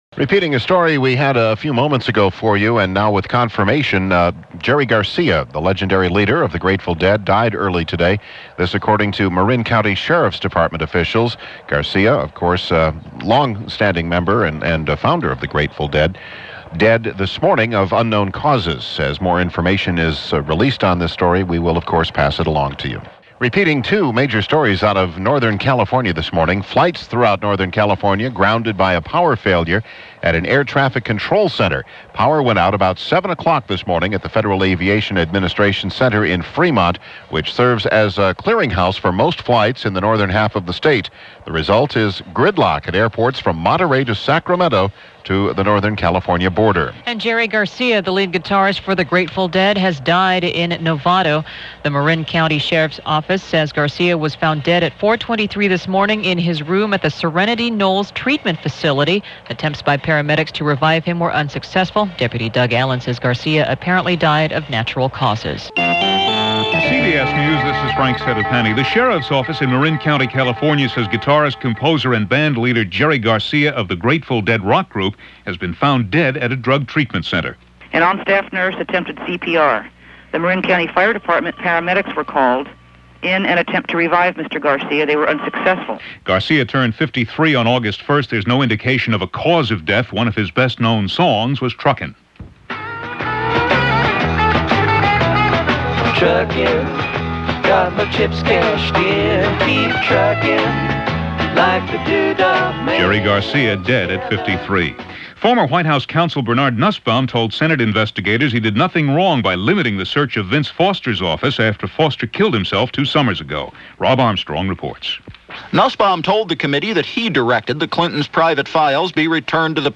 And that’s what else went on in the world via CBS News and reports from KNX in Los Angeles for August 9, 1995.
news-for-august-9-1995.mp3